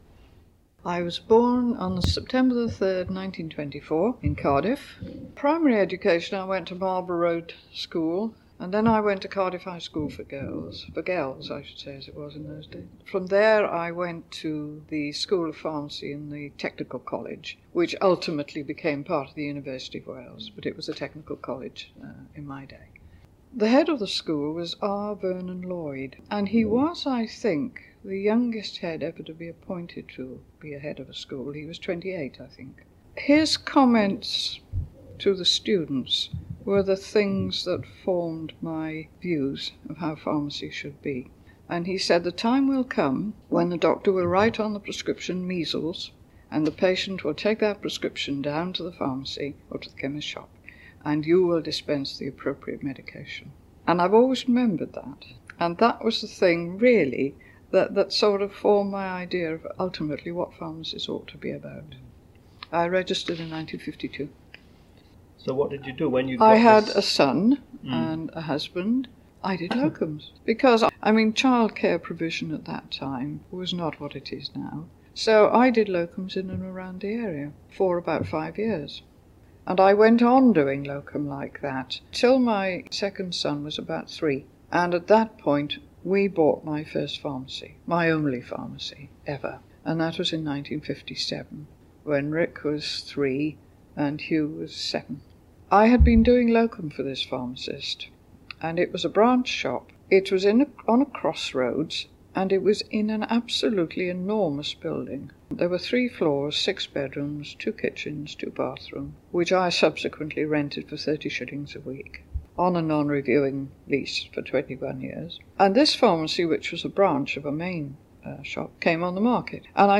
RCPharms Museum has a growing collection of oral history recordings where pharmacists past and present share their experiences in their own words.